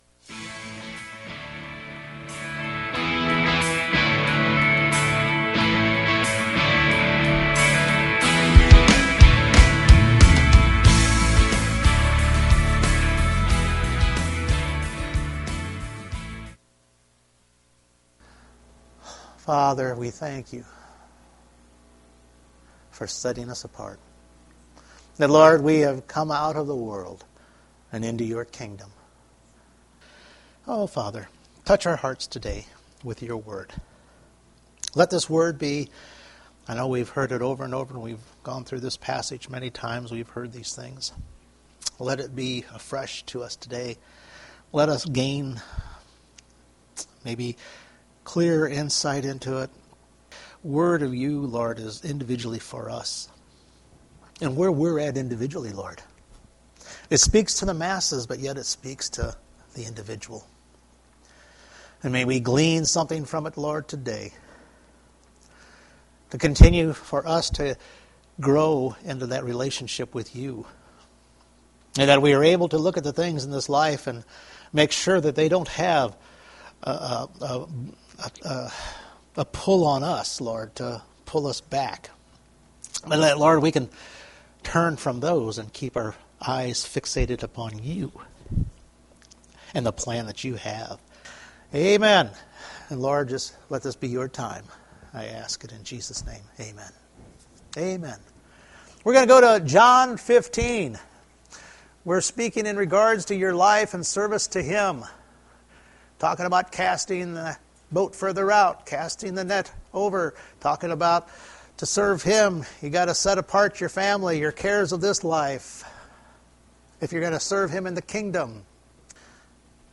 John 15:2 Service Type: Sunday Morning We are sharing on the Vinedresser and what happens to the branches on His vine.